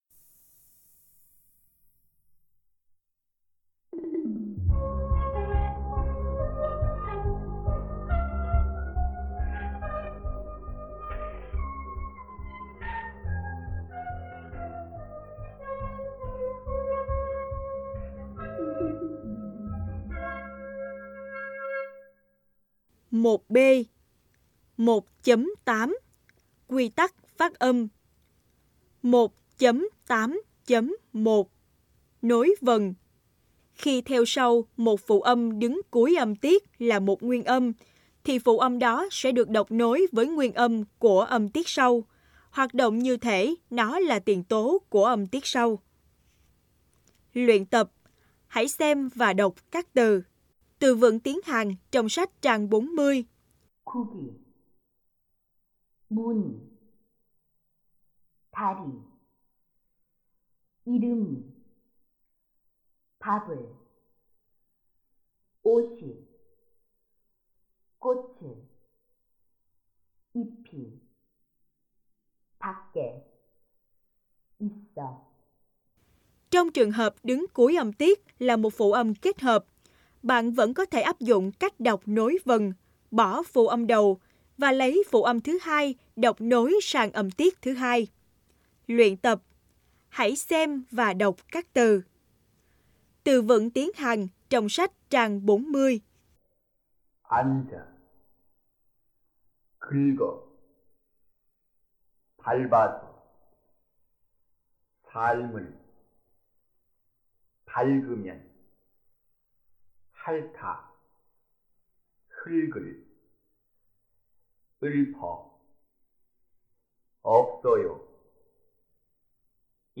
sách cũng kèm theo 2 băng cassette giúp bạn thực hành nghe tiếng Hàn theo giọng chuẩn.